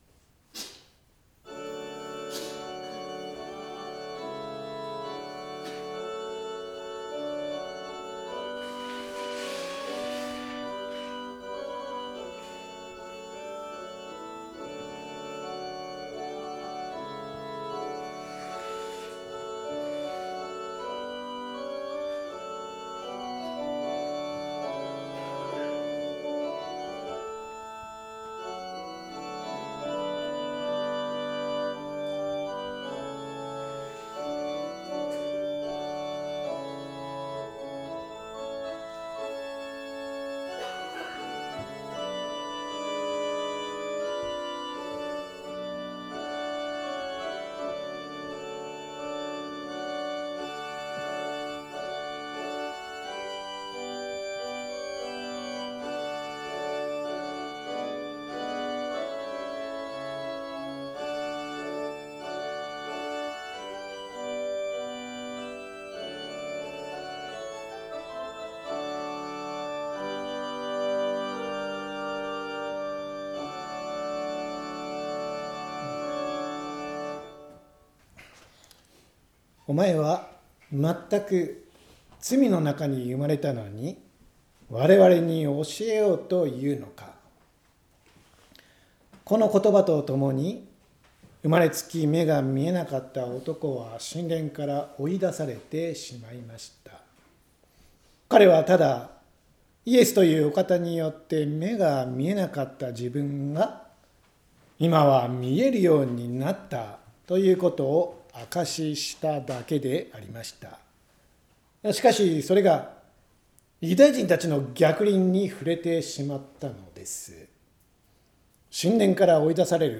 千間台教会。説教アーカイブ。
音声ファイル 礼拝説教を録音した音声ファイルを公開しています。